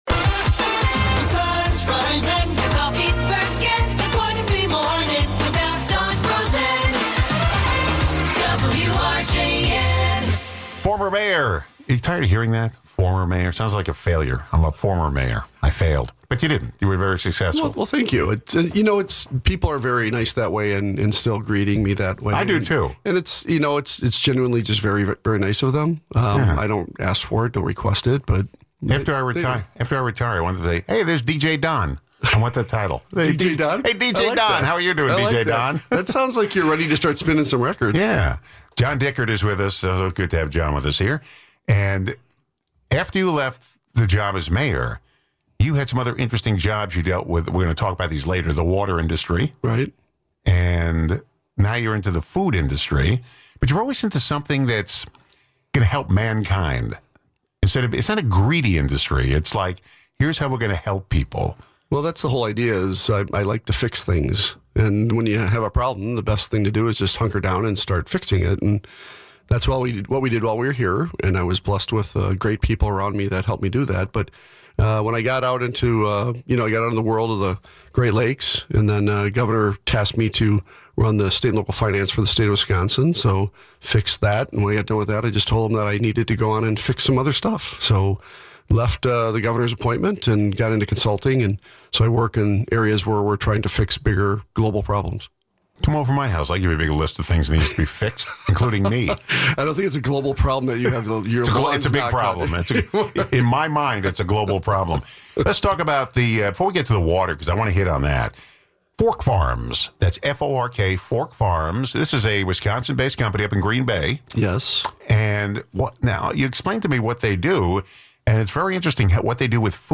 Former Racine Mayor, John Dickert, now a consultant for Fork Farms, a Green Bay, Wisconsin based company, discusses the future of food and water.